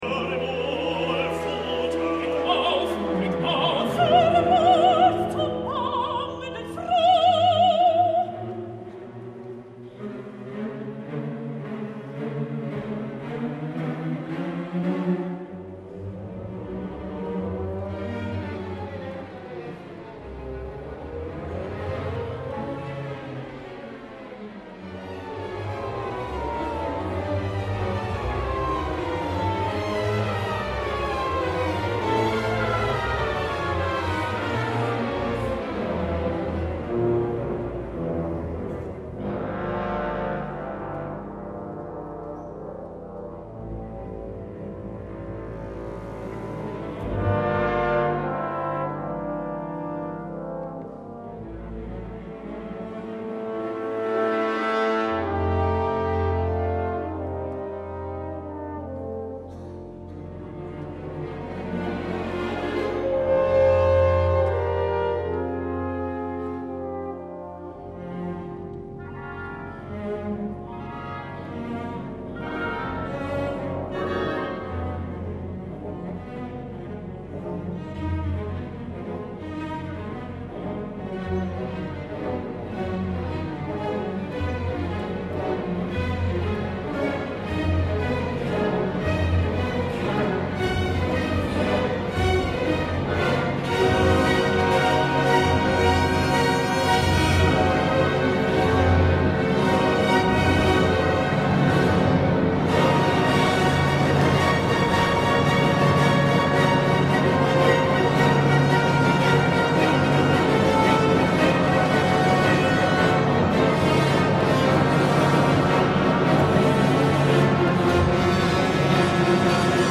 Lucerna, KKL, 30 d’agost de 2013